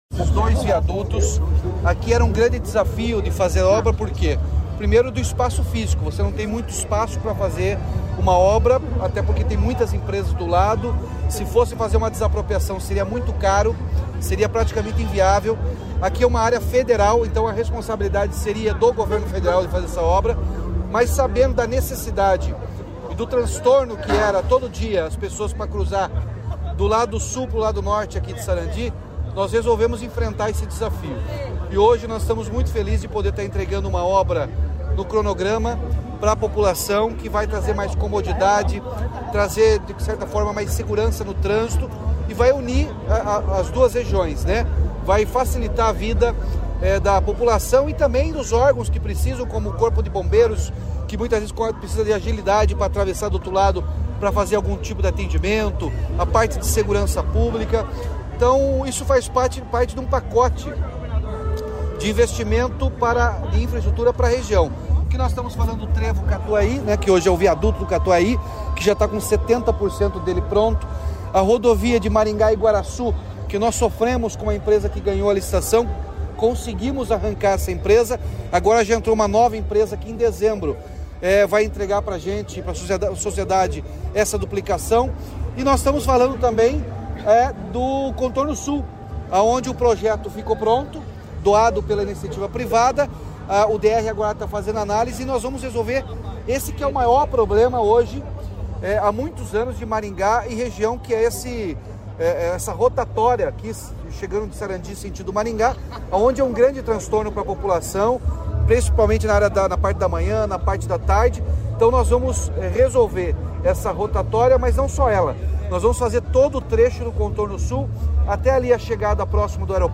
Sonora do governador Ratinho Junior sobre a inauguração dos viadutos em Sarandi sobre a BR-376
RATINHO - SARANDI.mp3